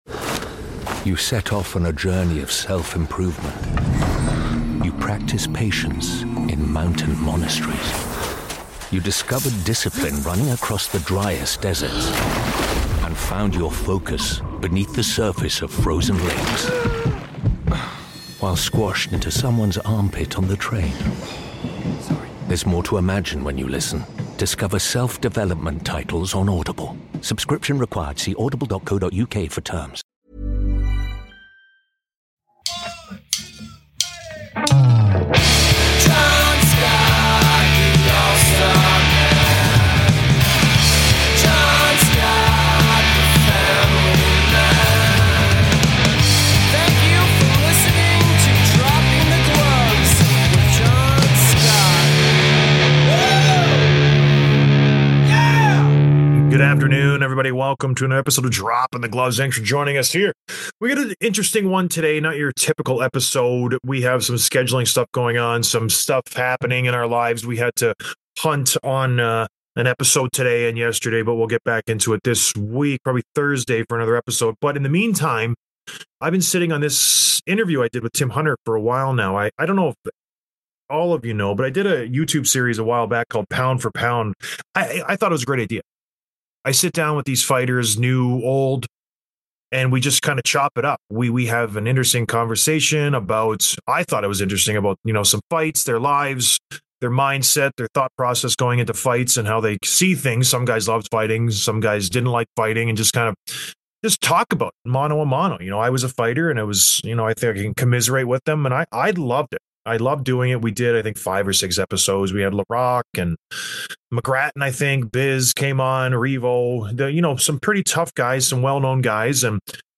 Download - 593: Interview with Jason Demers | Podbean